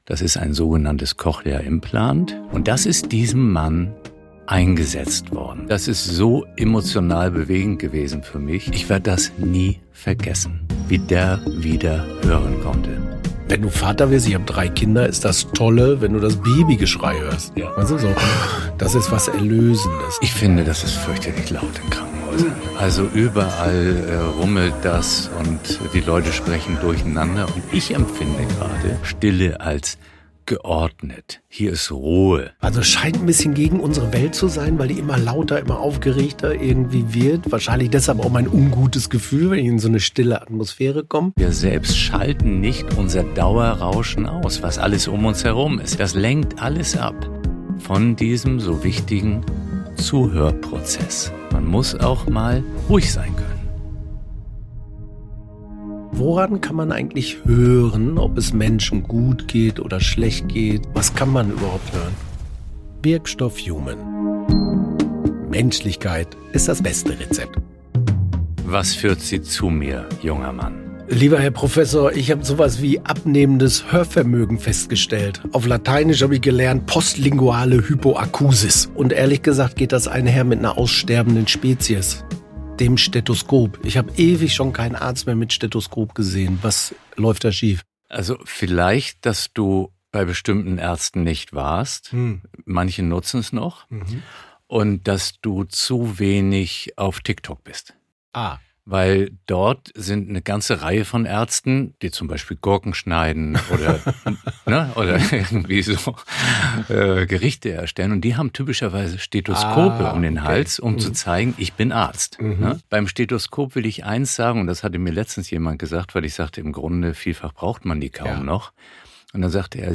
Ein Gespräch über Resonanz, Empathie und die Kunst, das Nichtgesagte zu verstehen.